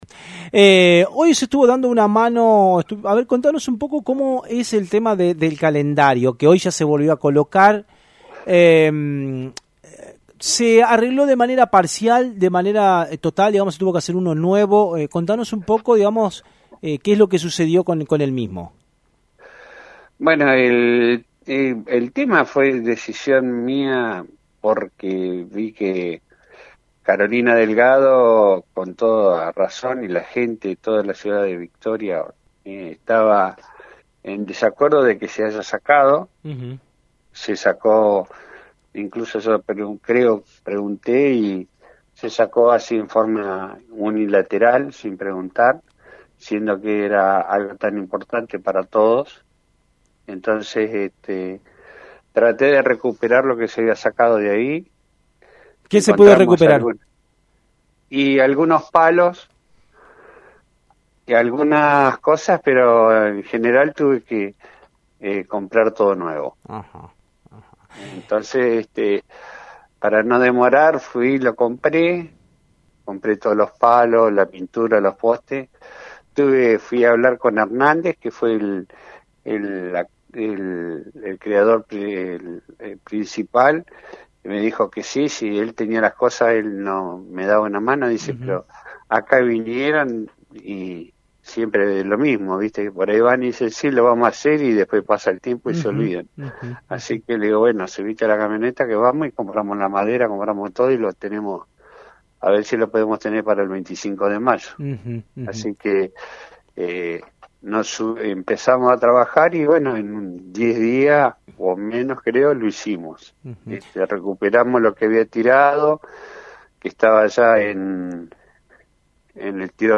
Claudio Larreguy – Concejal